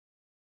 silence.wav